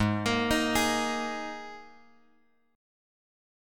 G# Suspended 2nd
G#sus2 chord {4 1 1 3 4 x} chord